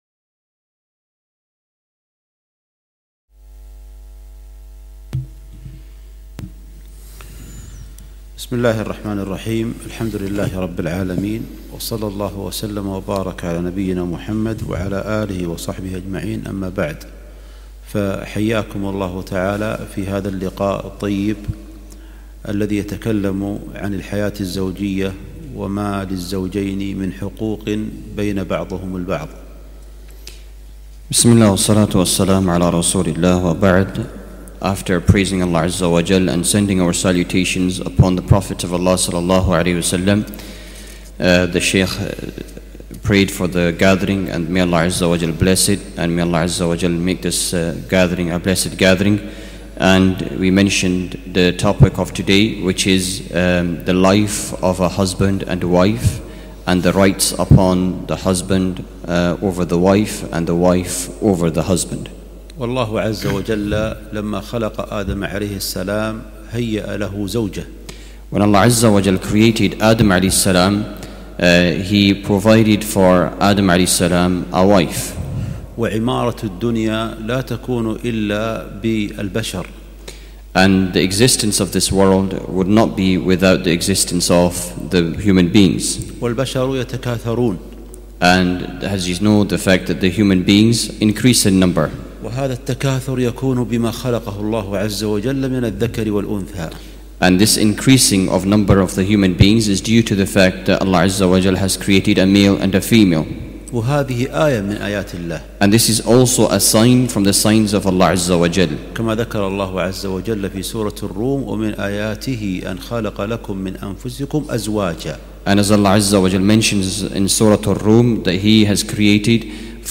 محاضرة عن الحياة الزوجية مترجمة للإنجليزي Tying the Knot